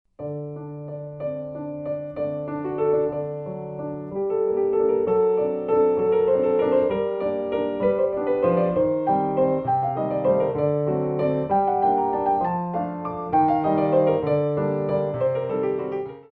Relevés en 3 Temps